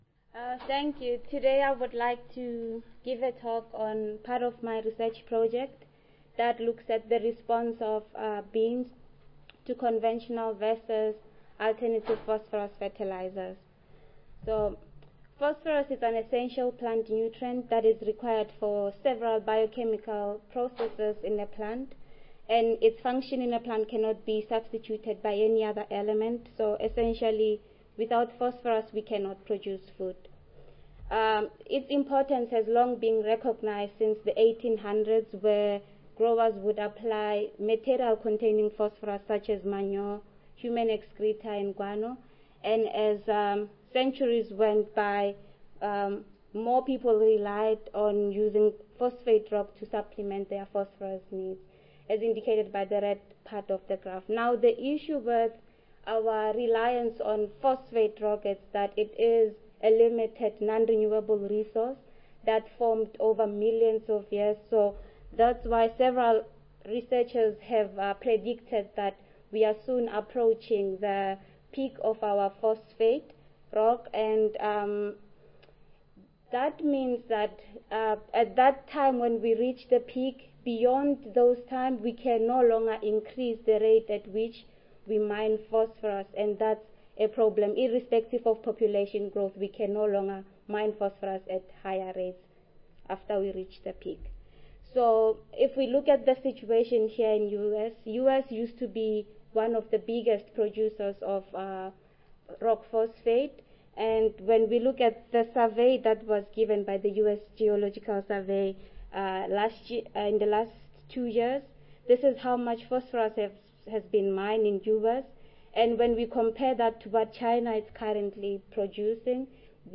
See more from this Division: SSSA Division: Soil Fertility and Plant Nutrition See more from this Session: Ph.D. Oral Competition II